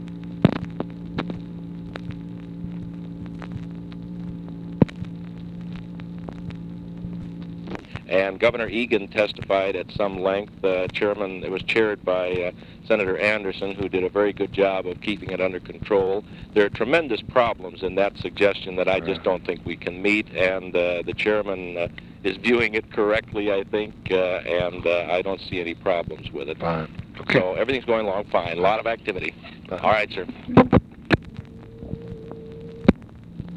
Conversation with ED MCDERMOTT, April 14, 1964
Secret White House Tapes